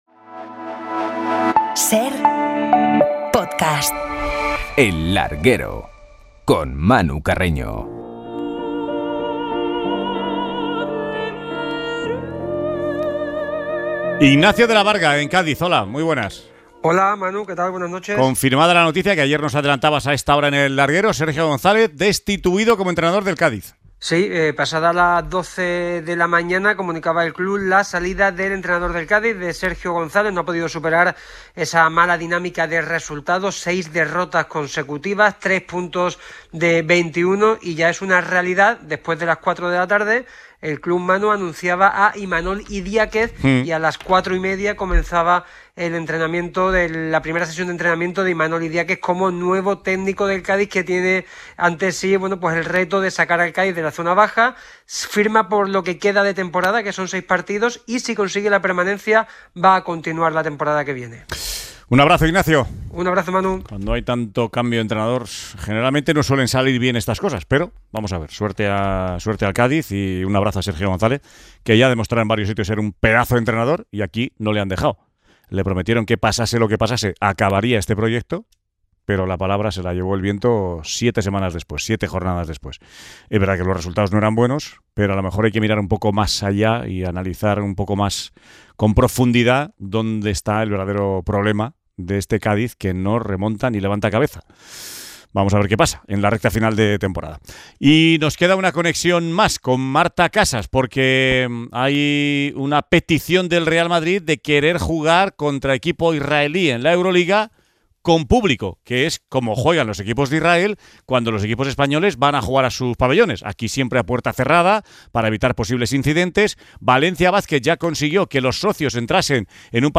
Entrevista con Rubi, entrenador del Almería, y Rafa Jódar suma y sigue en el Mutua Madrid Open